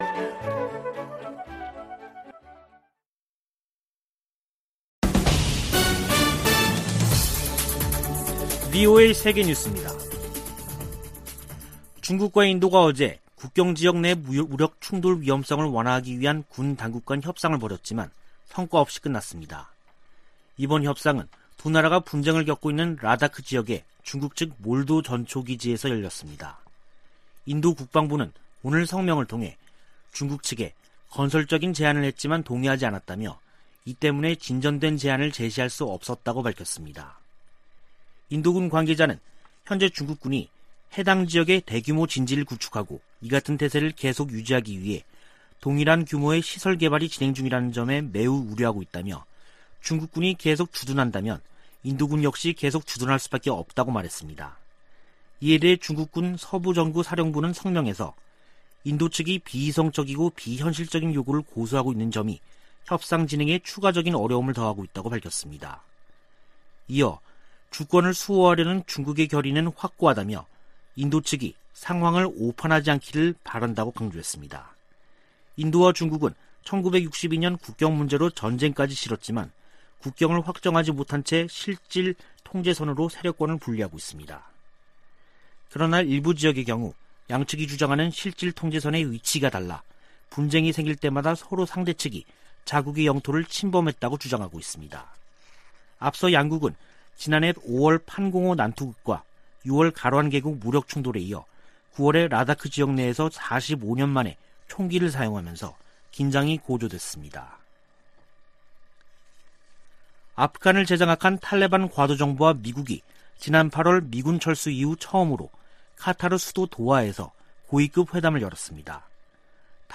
VOA 한국어 간판 뉴스 프로그램 '뉴스 투데이', 2021년 10월 11일 2부 방송입니다. 북한이 남북 통신연락선을 복원한 지 일주일이 지났지만 대화에 나설 조짐은 보이지 않고 있습니다. 미국은 지난해 정찰위성 2개를 새로 운용해 대북 정보수집에 활용하고 있다고 미 국가정찰국이 밝혔습니다. 세계 300여 개 민간단체를 대표하는 40개 기구가 10일 북한 노동당 창건 76주년을 맞아 유엔 회원국들에 공개서한을 보냈습니다.